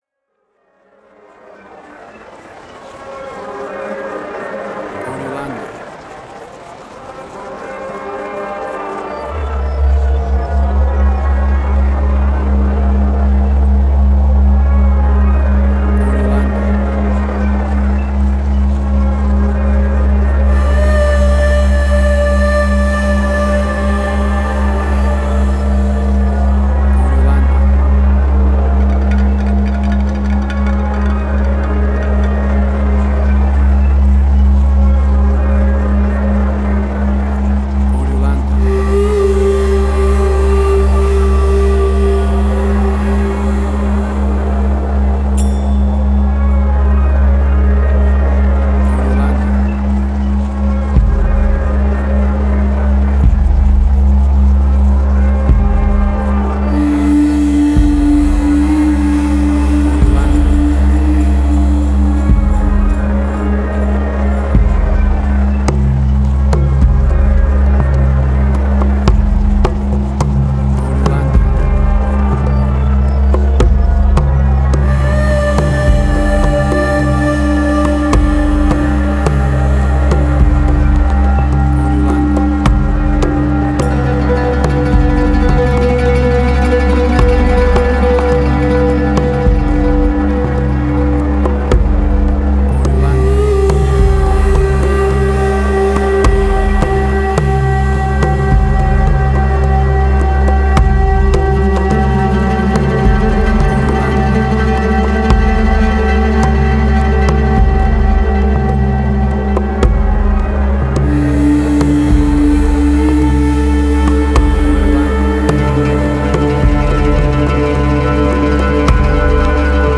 Middle Eastern Fusion.
Tempo (BPM): 107